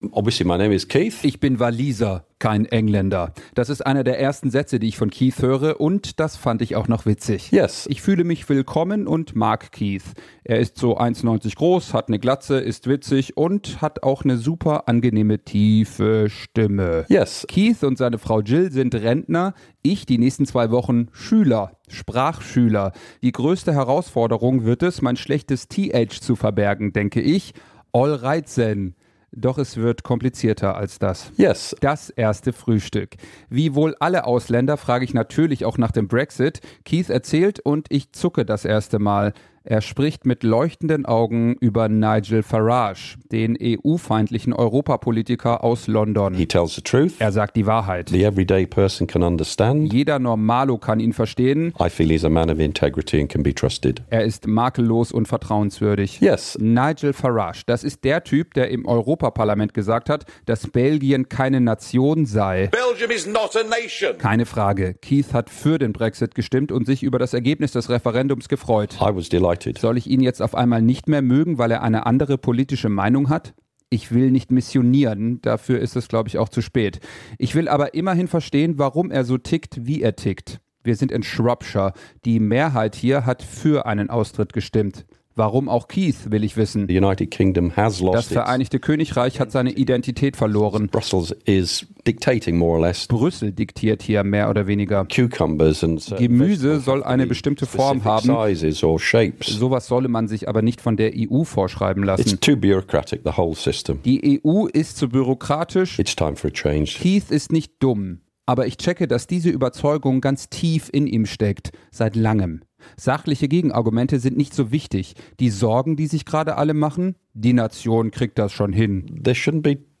SWR3-Audio: Beitrag anhören